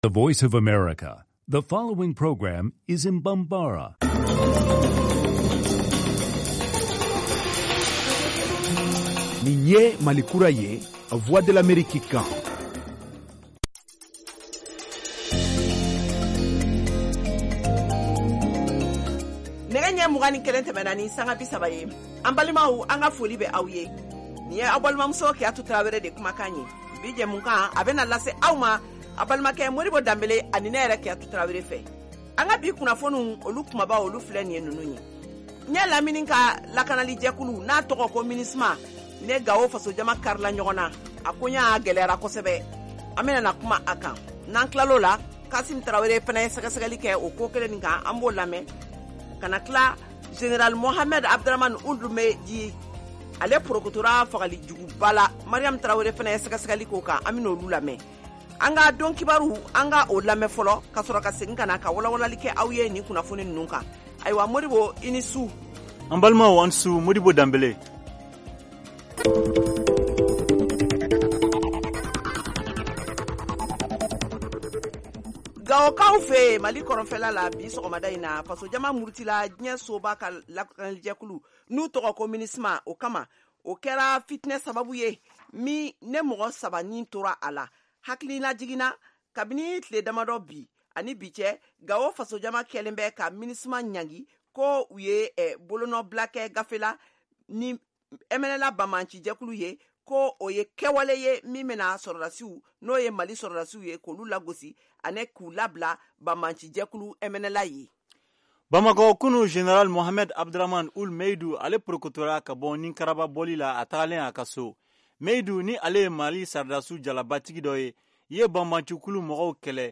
Emission quotidienne en langue bambara
en direct de Washington, DC, aux USA.